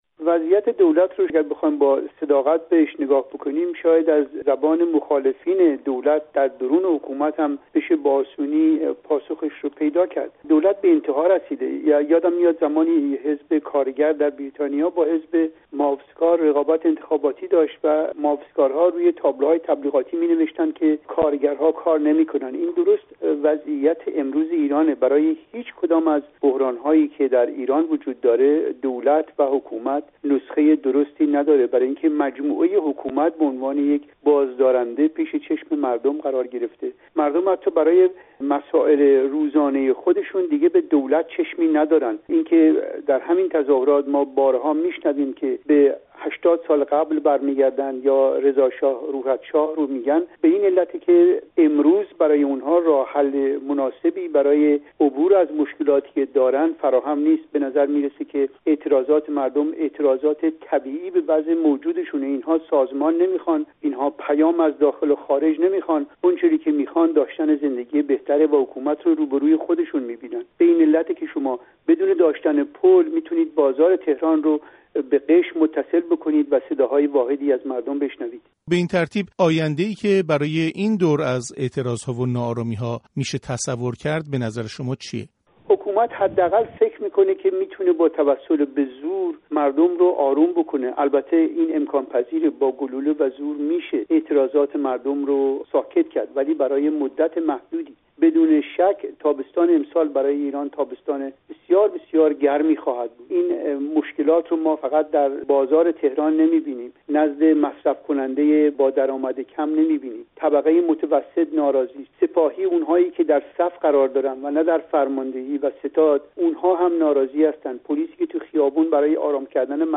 تحلیلگر سیاسی